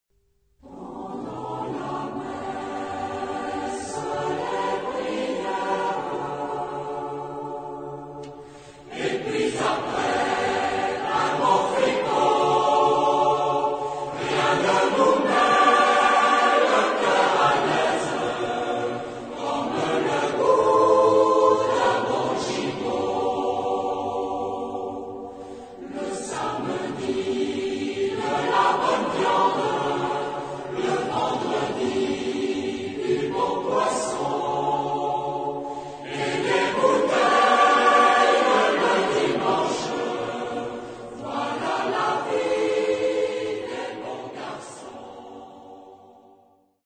Genre-Style-Forme : Chanson à boire ; Traditionnel ; Populaire ; Chanson ; Profane
Type de choeur : SATB  (4 voix mixtes )
Origine : Cantal ; Auvergne (France)